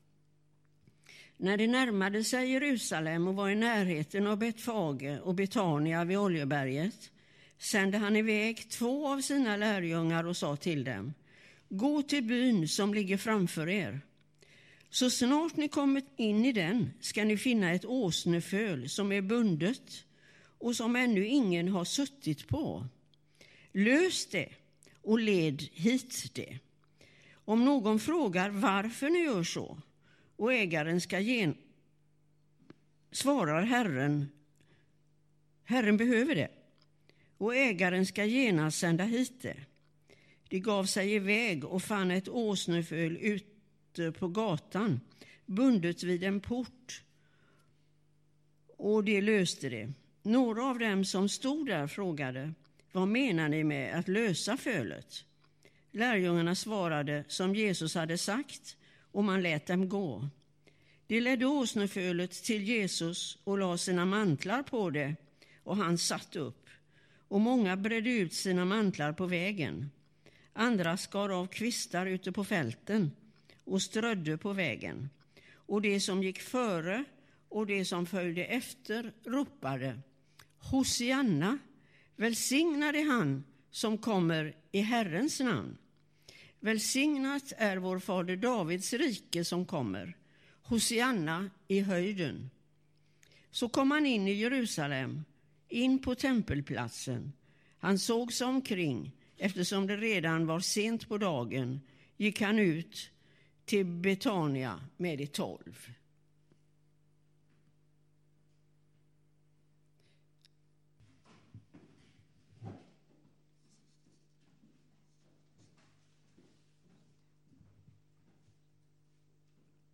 2019-04-14 Predikan av